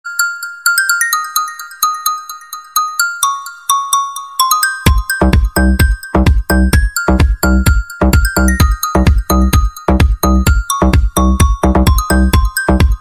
Kategorien: Wecktöne